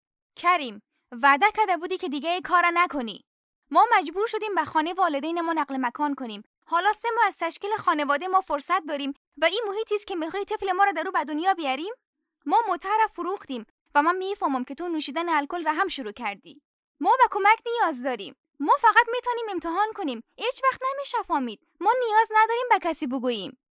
Female
Young
Dubbing